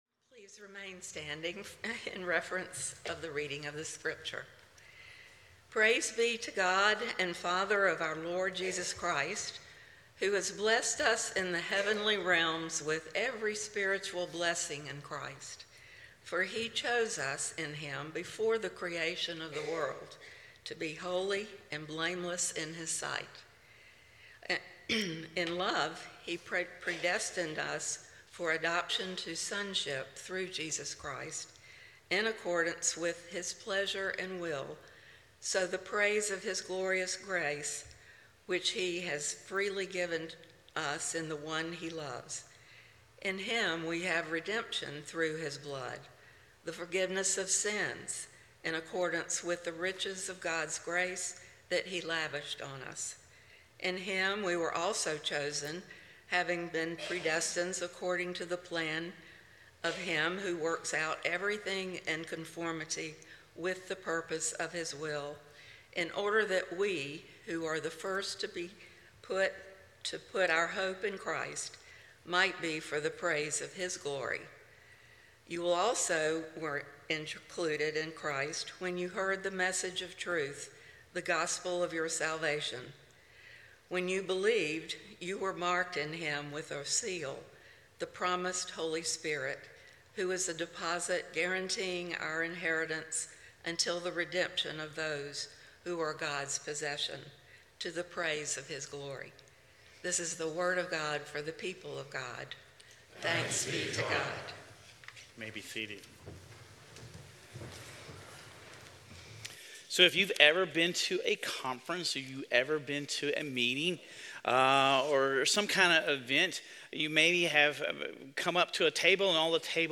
Sermon Reflections: If you couldn't write your name on a name tag but had to use words that identify you, what would you write?